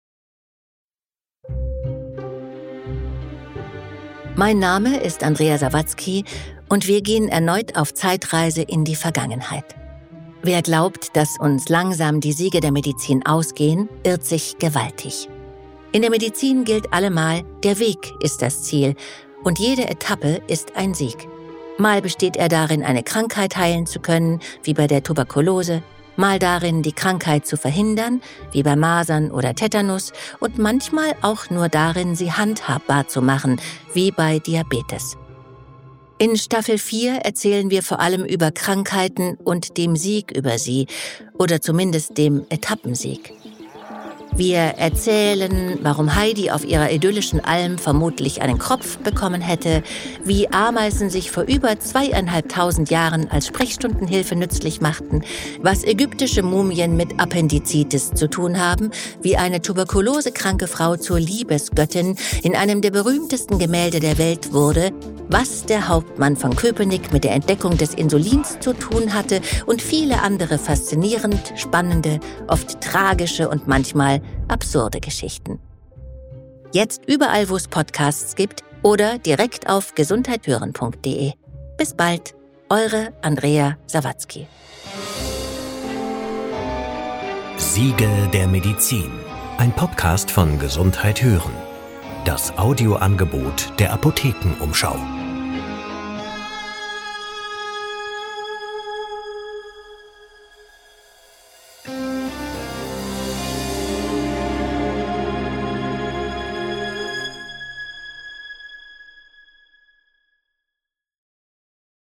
Seid dabei, wenn Schauspielerin Andrea Sawatzki sich erneut auf